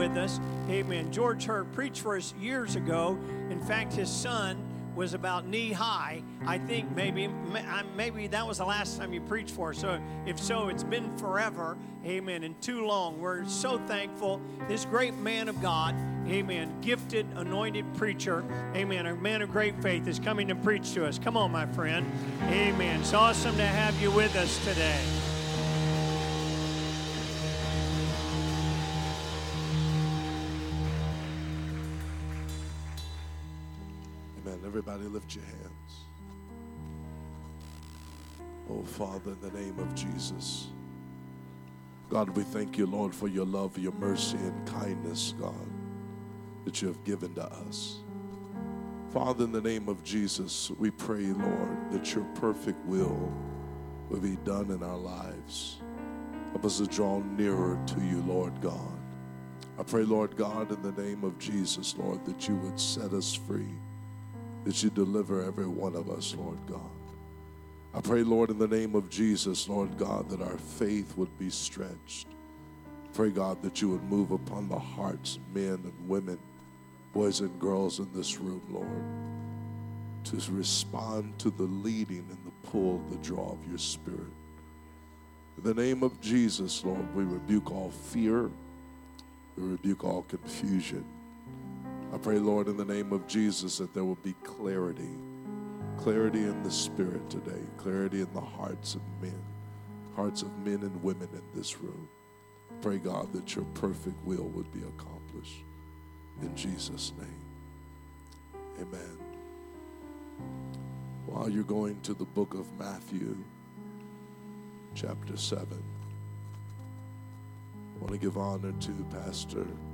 Sermons | Elkhart Life Church
Sunday Service - Part 5